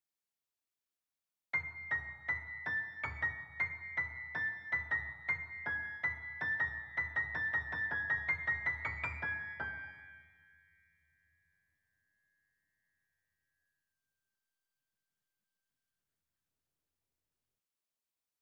I have a kind of a theme, for the canon:
These “progressing sixteenth notes”, which ultimately turn into entire groups, must mean something, I suspect.